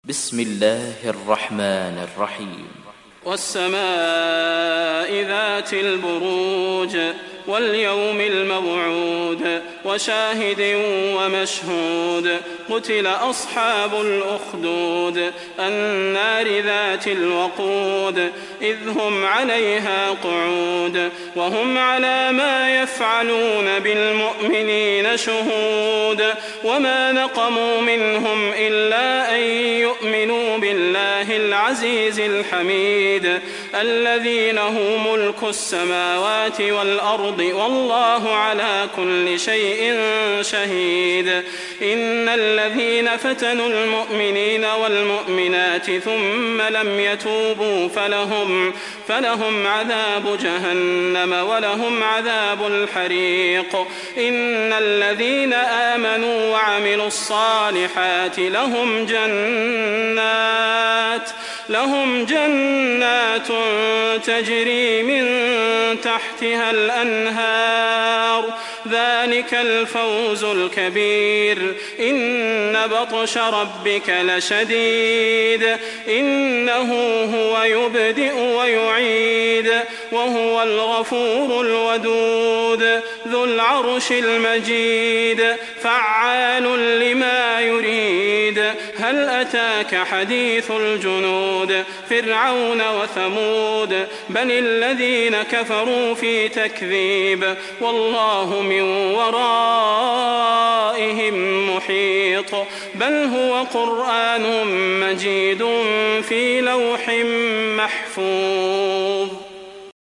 تحميل سورة البروج mp3 صلاح البدير (رواية حفص)